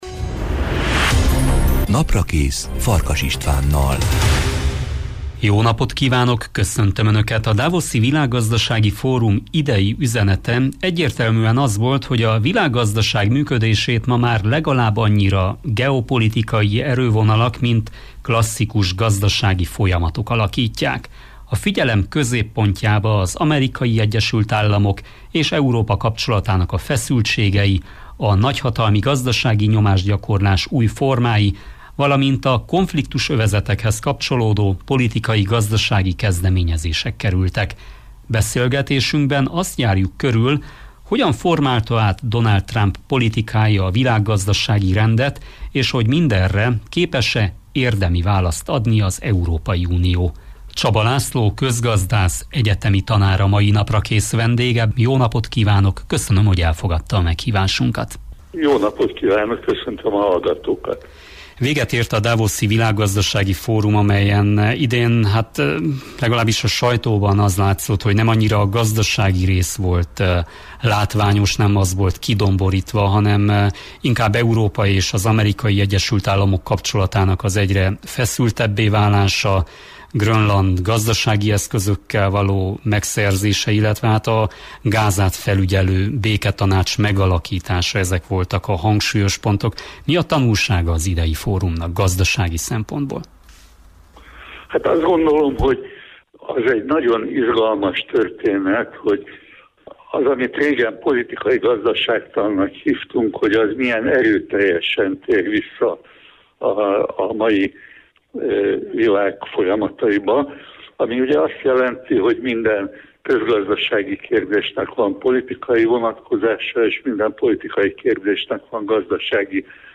Beszélgetésünkben azt járjuk körül, hogyan formálta át Donald Trump politikája a világgazdasági rendet és hogy minderre képes-e érdemi választ adni az Európai Unió.